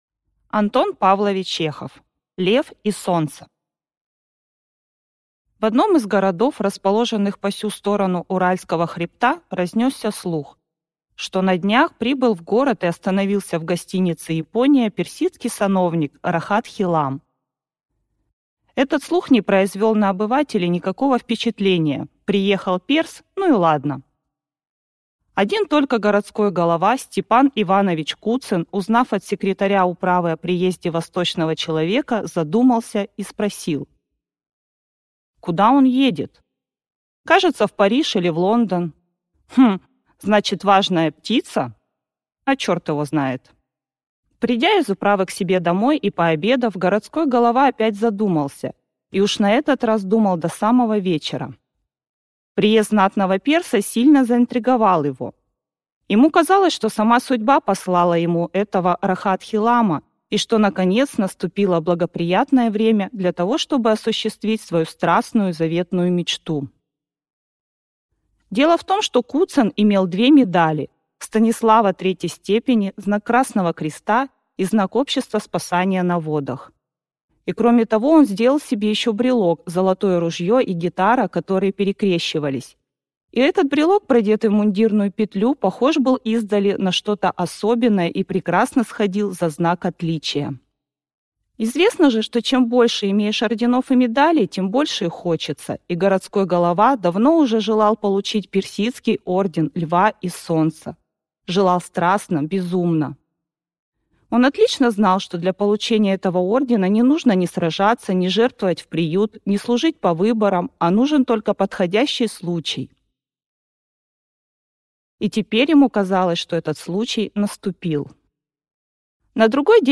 Аудиокнига Лев и солнце | Библиотека аудиокниг